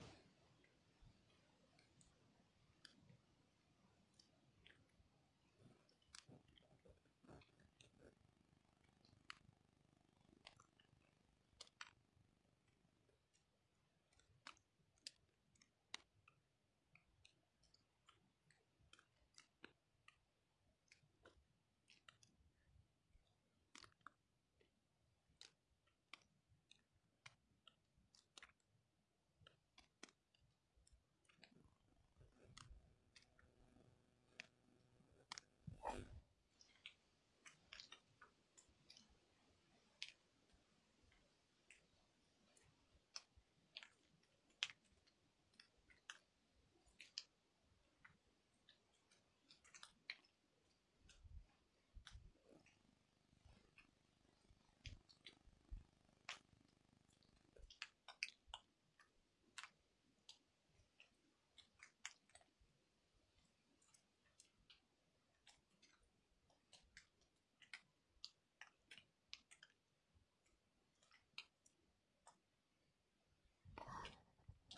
大洞穴里的滴水声
描述：像在一个大洞穴里的滴水声。用FL Studio编辑，其实是用嘴发出来的声音，哈哈哈。
标签： 滴落 滴水 掉落 水滴 混响 洞穴 spash
声道立体声